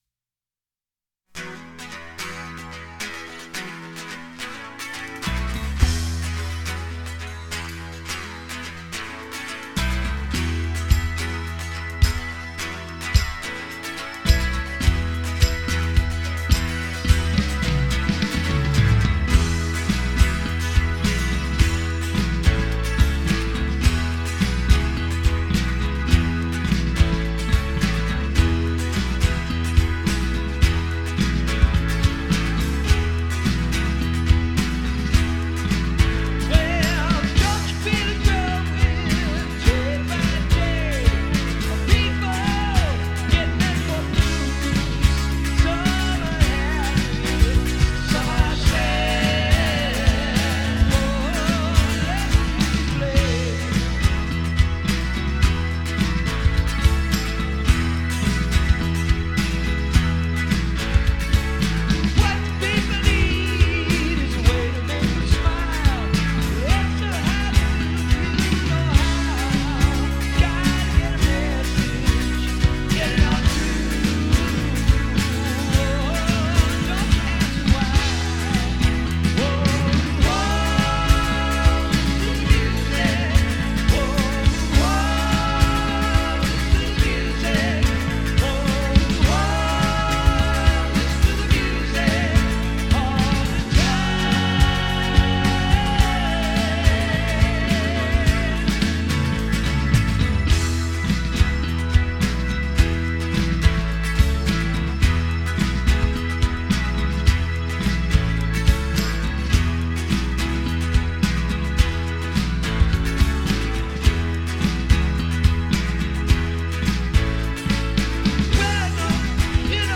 Vocal Lead and Keys
Guitar, Lead Guitar and Vocals
Percussion and Vocals